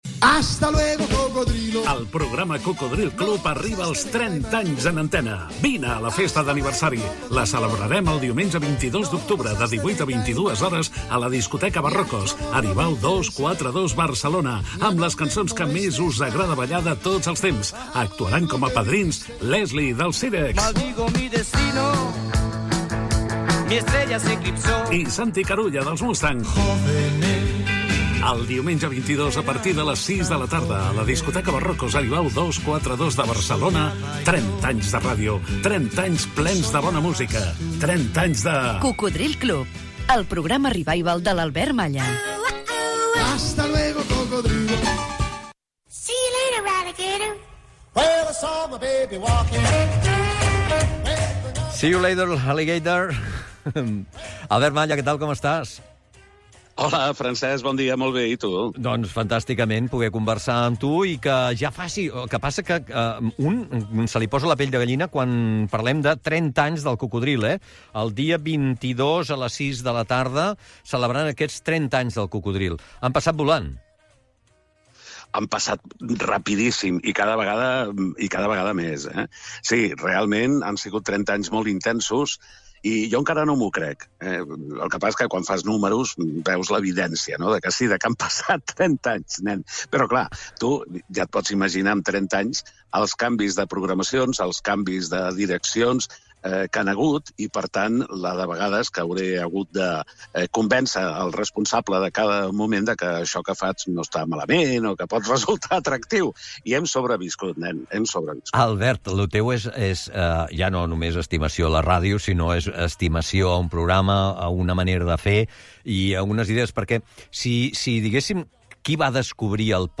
394f8cd6ffe7d4f281bebfe0365afd571e30cc03.mp3 Títol Premià Mèdia Emissora Premià Mèdia Titularitat Pública municipal Nom programa Matinal Premià Mèdia Descripció Anunci de la festa, el 22 d'octubre de 2023, dels 30 anys del programa "Cocodril Club". Entrevista